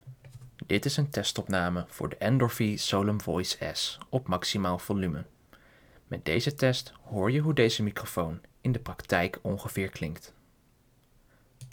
De opnames klinken natuurgetrouw en er is geen sprake van gekraak, gezoem, of overmatig achtergrondgeluid.
Endorfy Solum Voice S - 100% volume - Cardioid - 30 cm afstand